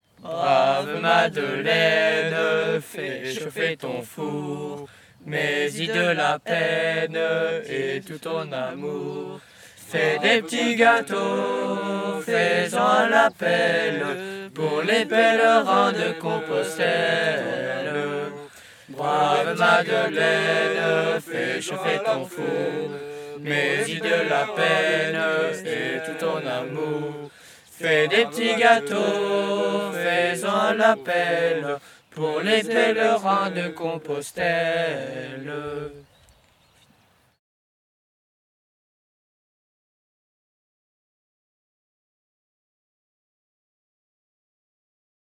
Les Scouts d'Europe d'Arlon
Type : chant de mouvement de jeunesse | Date : 16 mars 2024